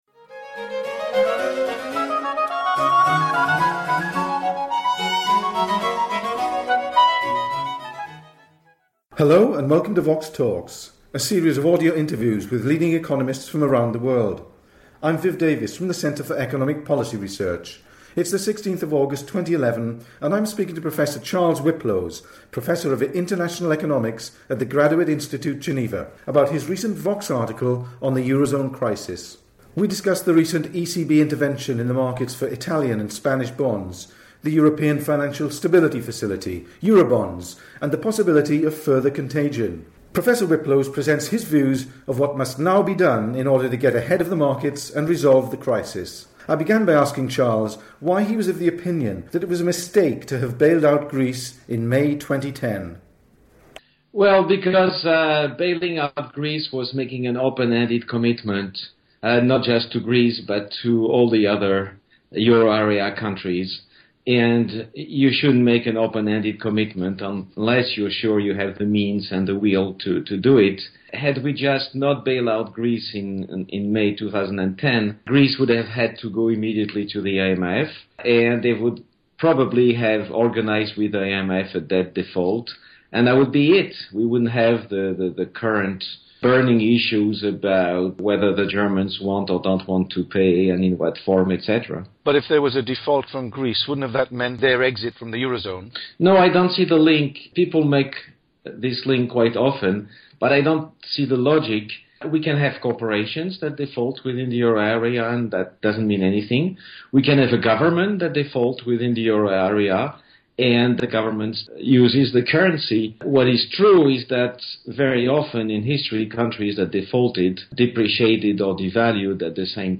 The interview was recorded on 16 August 2011.